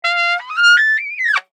Download Silly sound effect for free.